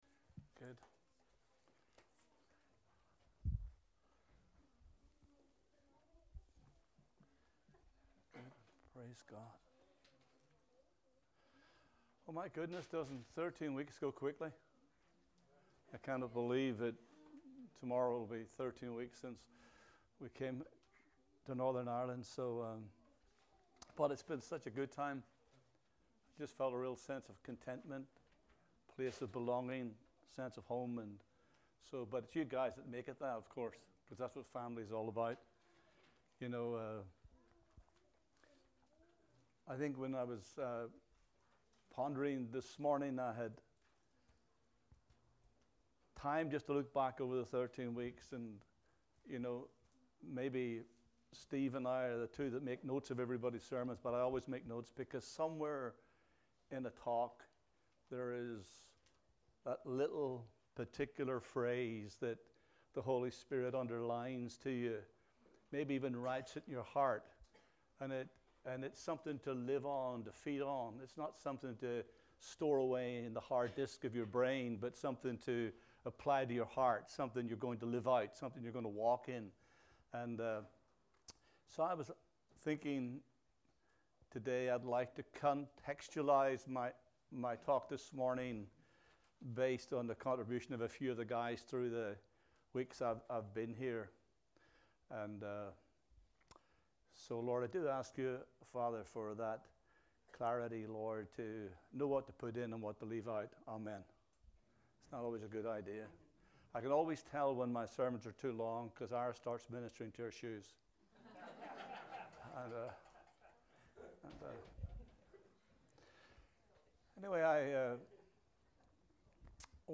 King's Way Church Dunmurry NI
A message from the series "All Messages."